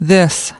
8. This /ðɪs/ : đây / này